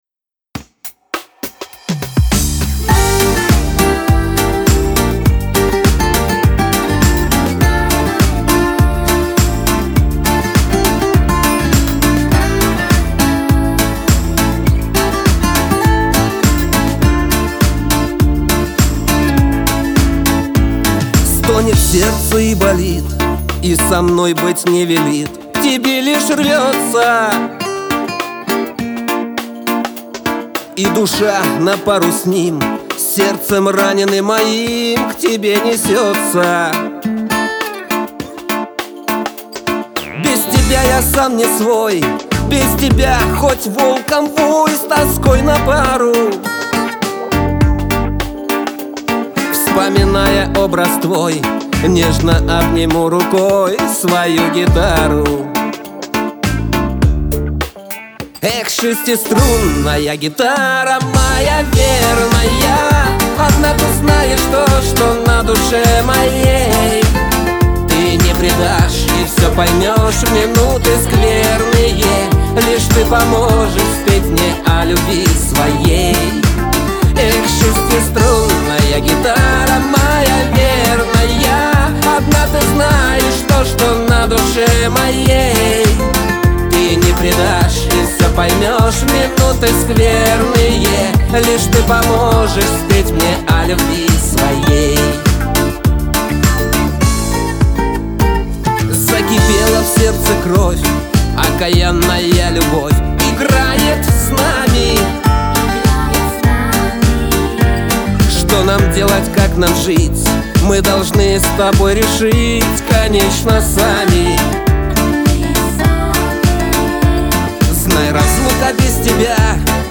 Категория: Шансон